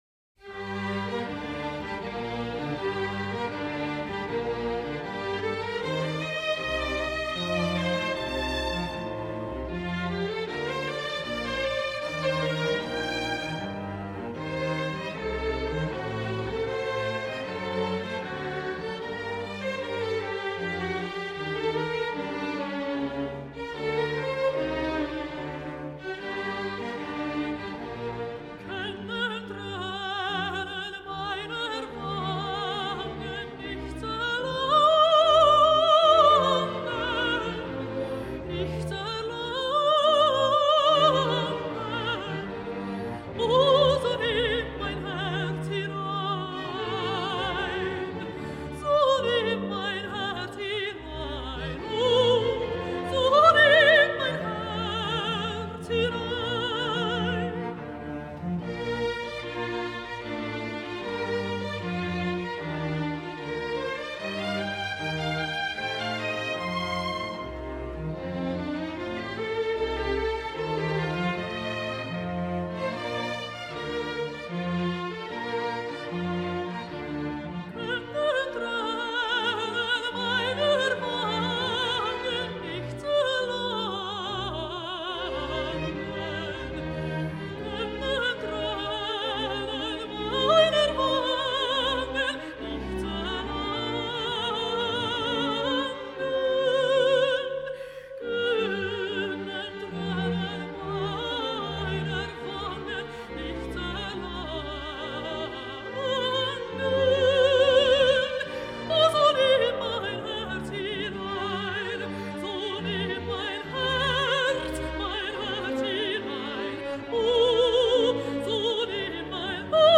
Aria Alto
Violino I/II, Continuo
This entry was posted in Musica Sacra.